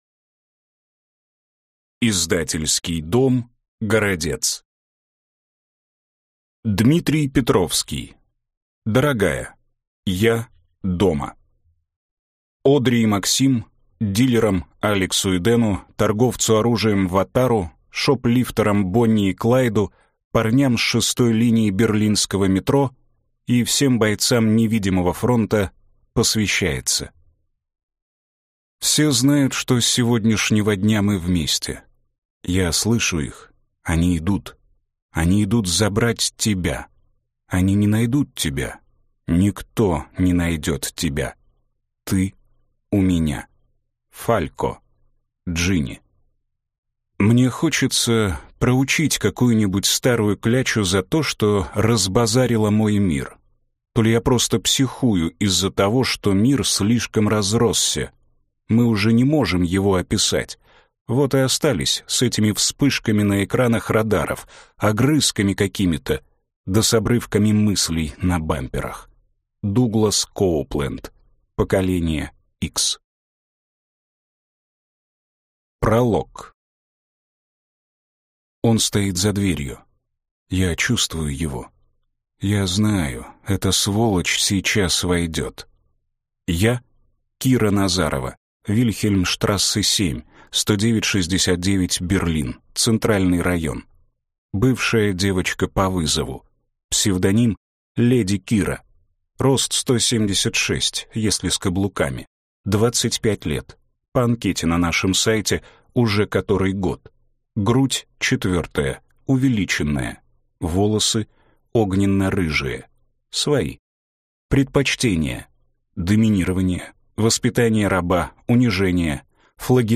Аудиокнига Дорогая, я дома | Библиотека аудиокниг